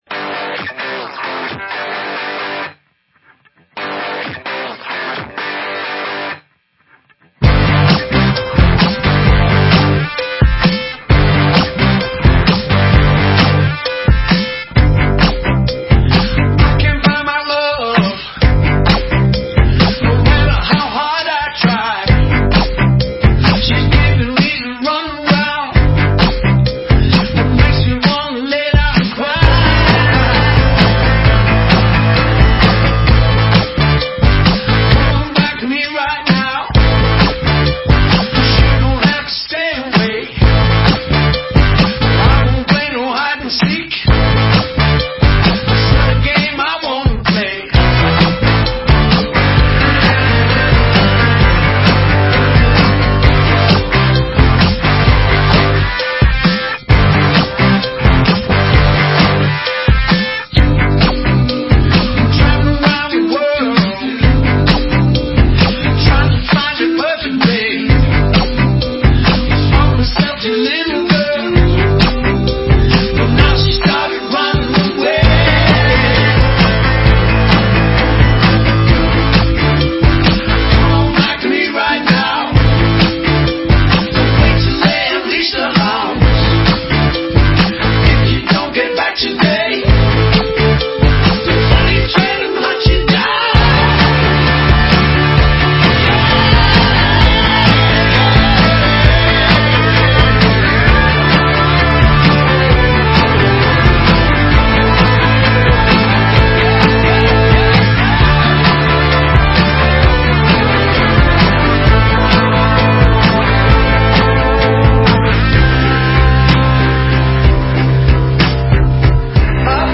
британского певца